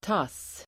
Ladda ner uttalet
Uttal: [tas:]